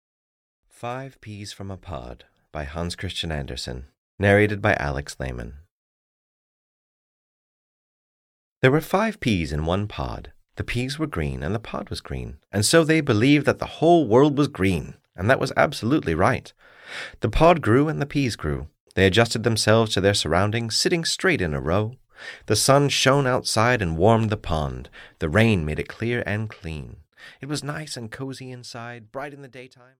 Audio knihaFive Peas from a Pod (EN)
Ukázka z knihy